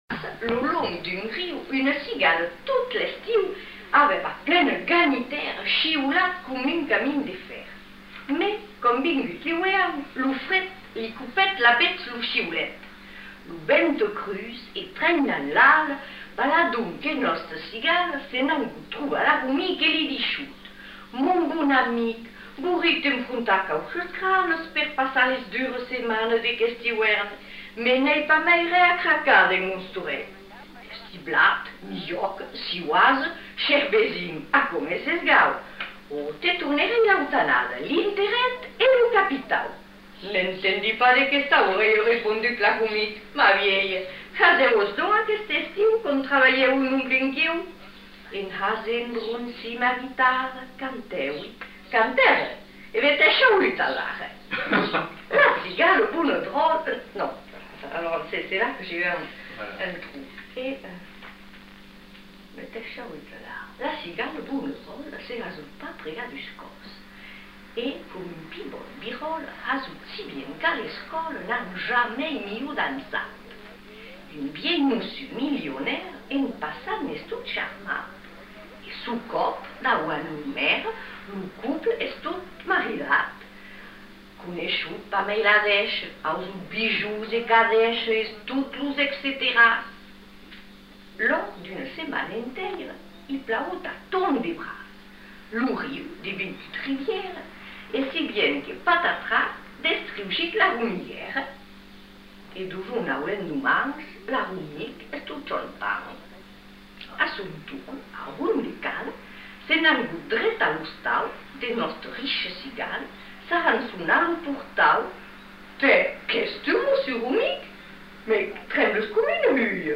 Aire culturelle : Bazadais
Lieu : Captieux
Genre : conte-légende-récit
Effectif : 1
Type de voix : voix de femme
Production du son : récité